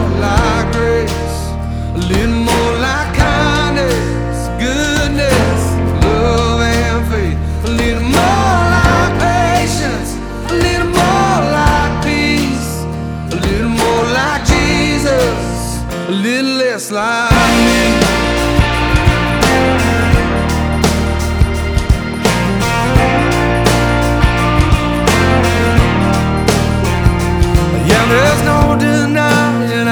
• Christian